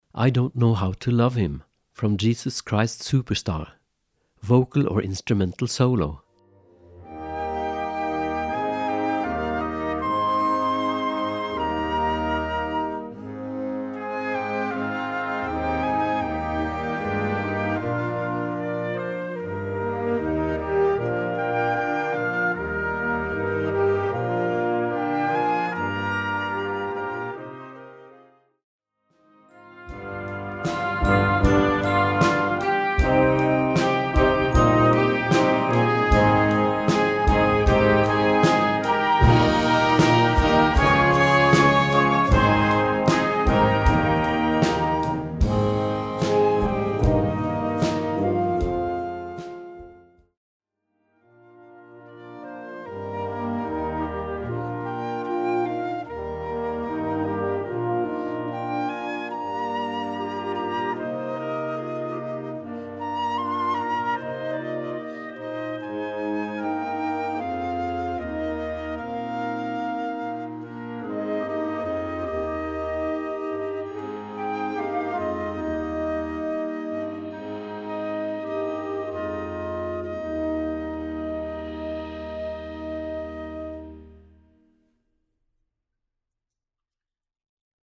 Gattung: Young Band Entertainment
Besetzung: Blasorchester
This is a ballad